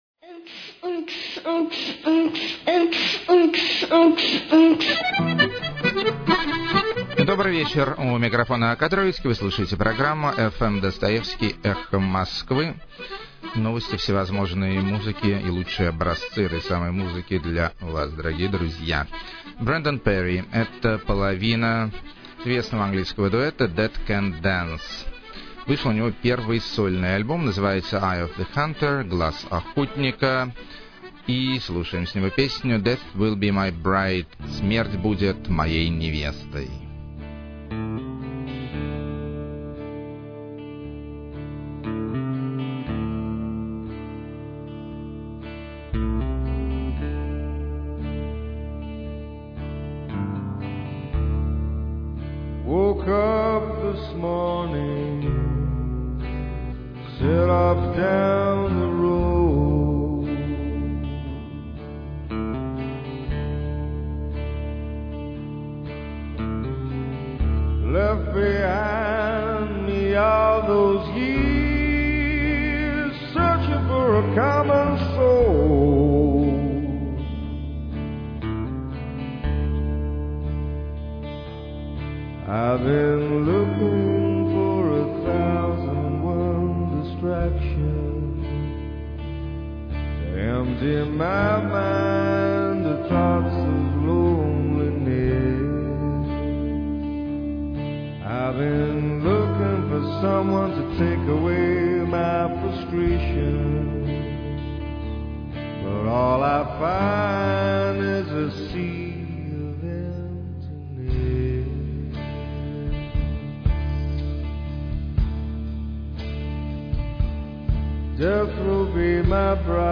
Свинг С Легким Футуристическим Привкусом.
Футуризм С Легким Свинговым Привкусом.
Народная Карпатская Мистика.